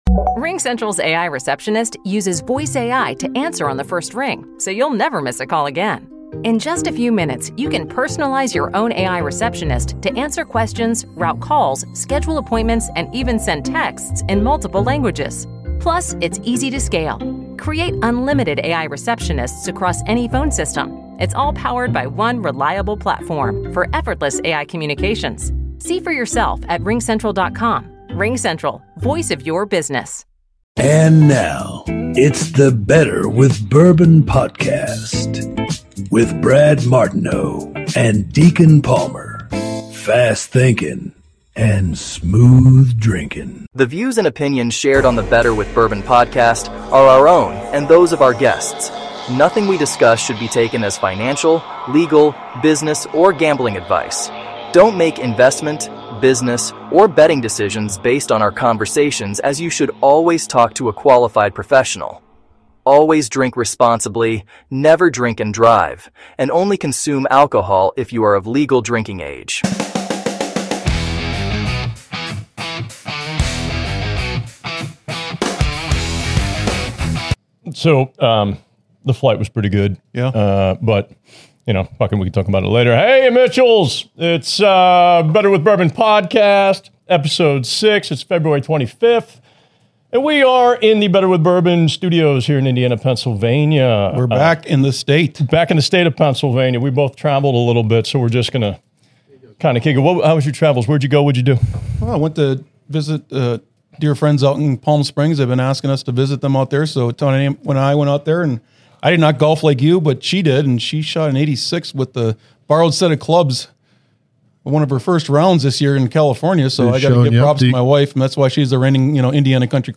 The conversation then turns to Anthropic’s escalating standoff with the Department of Defense and a brewing industry dispute as Anthropic accuses Deepseek and two other Chinese firms of using distillation on Claude to reverse‑engineer and train lower‑cost LLMs. Through sharp analysis and candid banter, the hosts separate signal from noise, explore the technical and ethical stakes, and consider how these developments reshape trust, regulation, and the business of AI while sipping a great new bourbon.